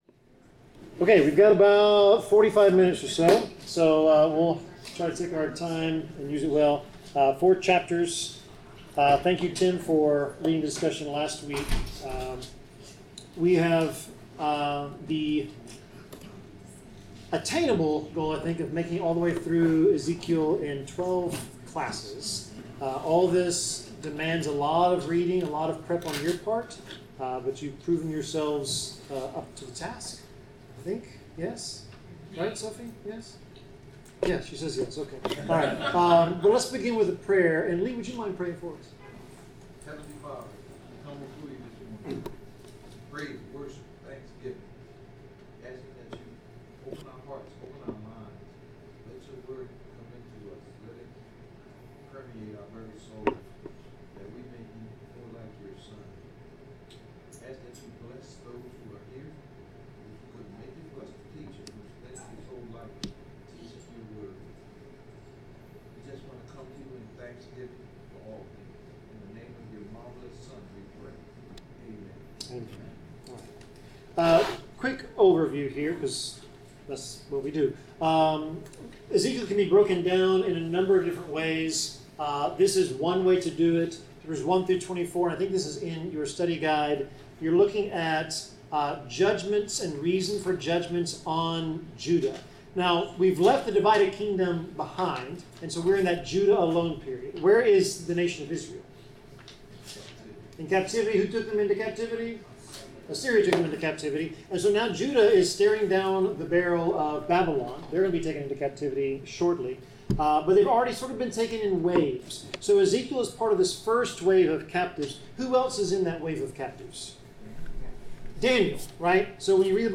Bible class: Ezekiel 4-7
Service Type: Bible Class